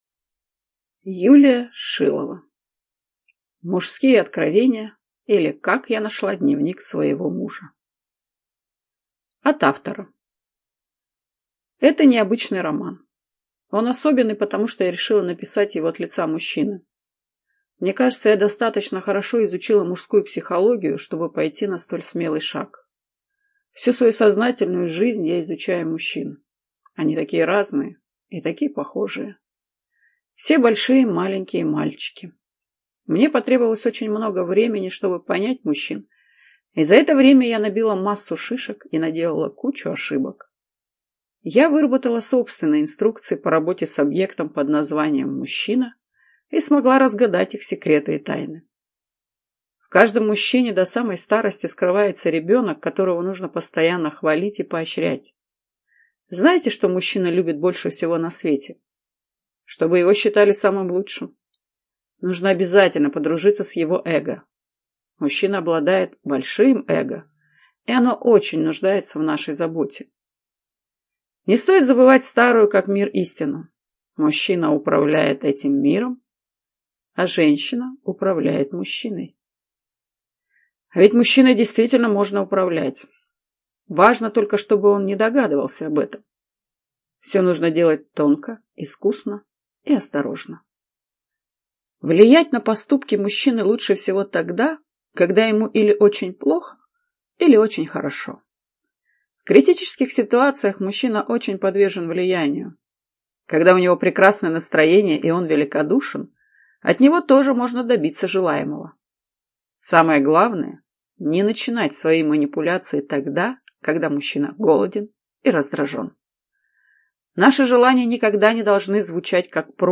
ЖанрЛюбовная проза